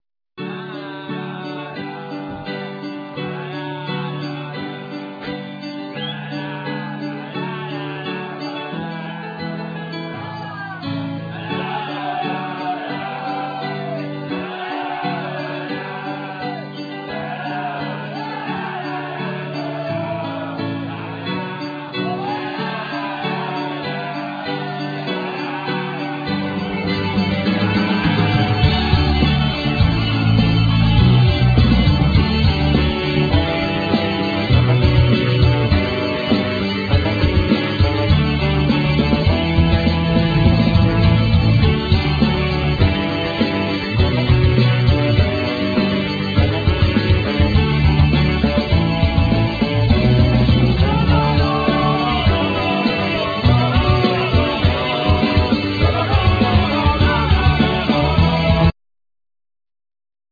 Guitar,Keyboards,Vocal
Tennor saxophne,Vocal
Bass,Vocal
Brushes,Stetce